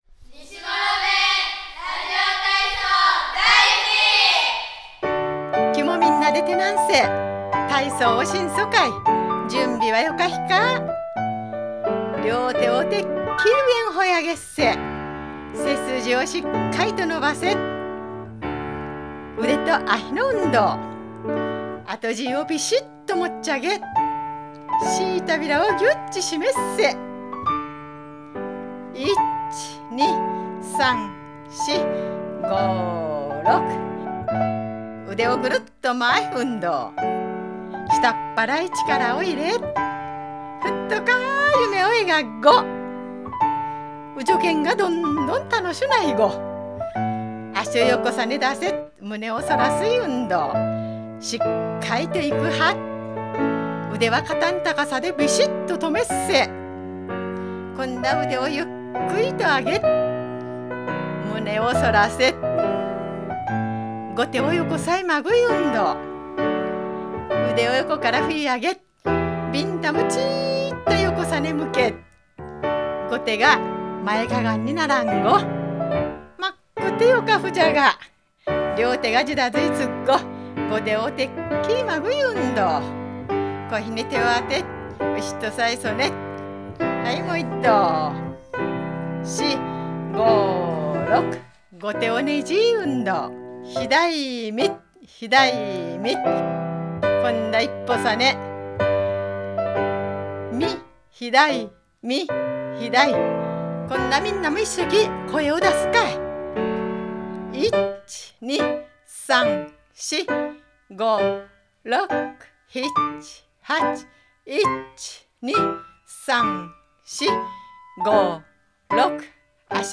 西諸弁ラジオ体操／宮崎県小林市公式ホームページ -Kobayashi City-